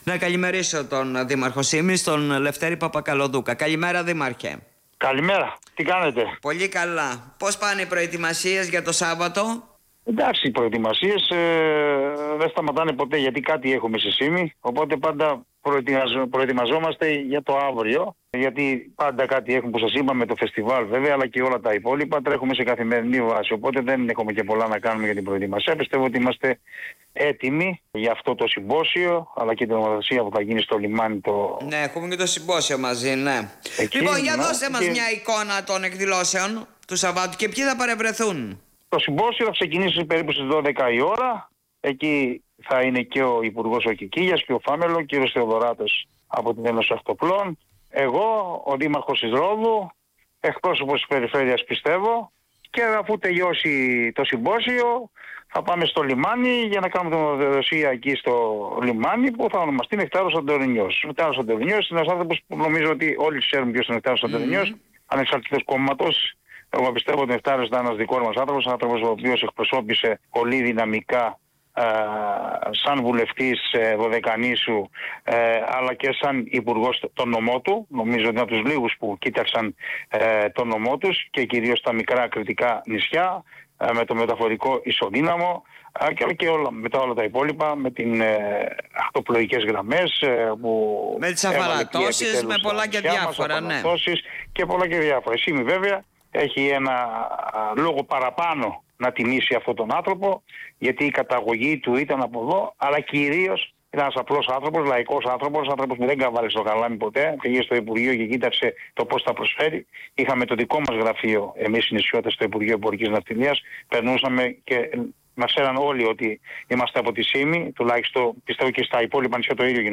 Ο δήμαρχος Σύμης Λευτέρης Παπακαλοδούκας μίλησε σήμερα στον topfm